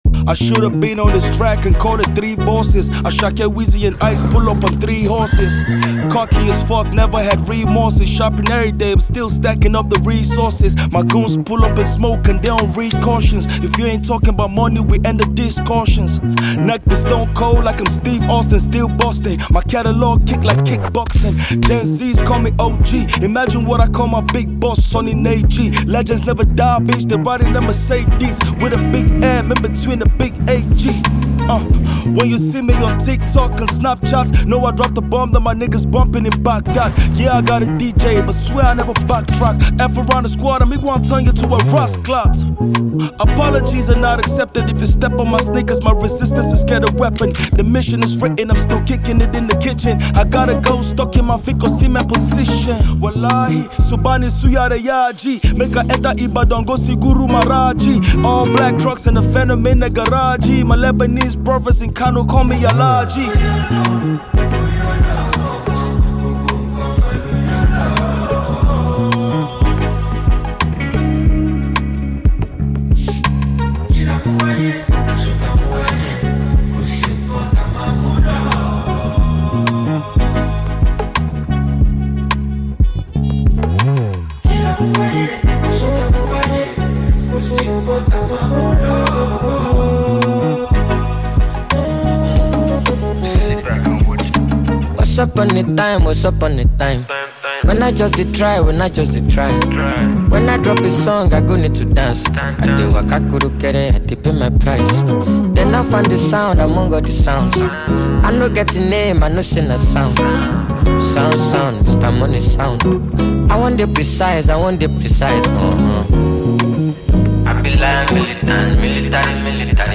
a multi-talented Nigerian rapper